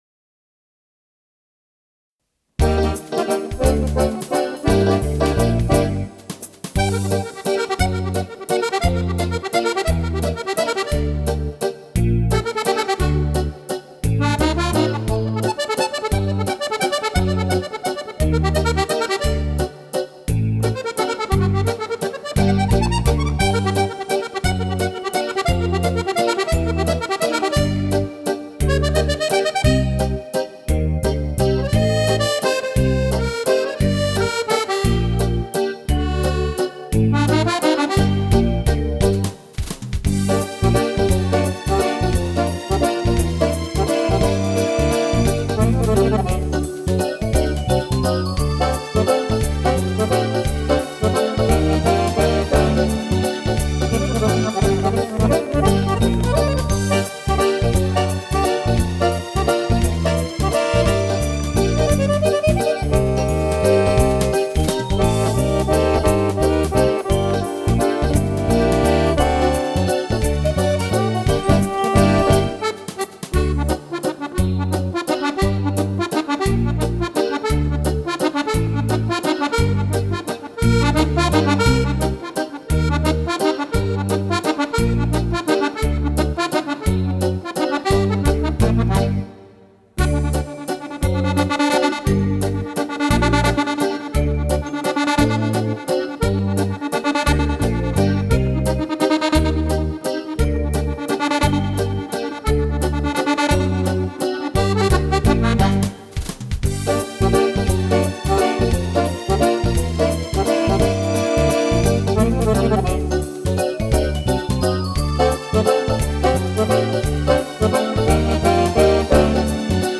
Brani per fisarmonica solista.